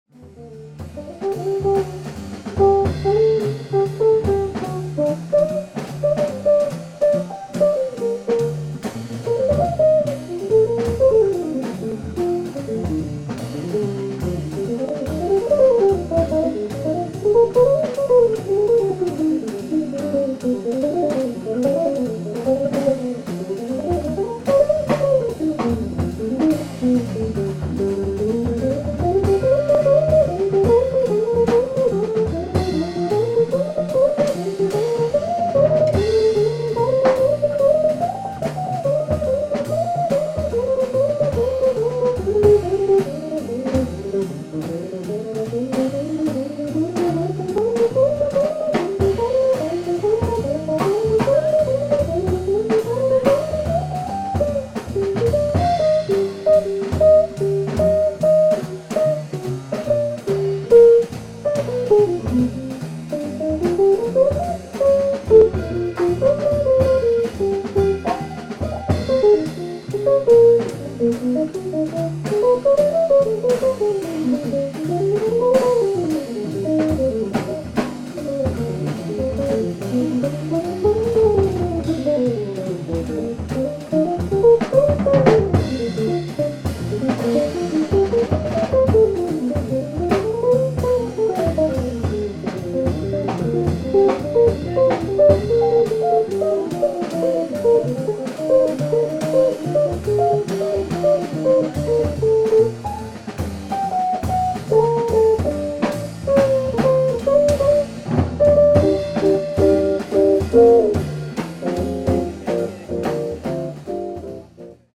ライブ・アット・ヴィレッジ・ヴァンガード、ニューヨーク・シティー 11/07/1996
※試聴用に実際より音質を落としています。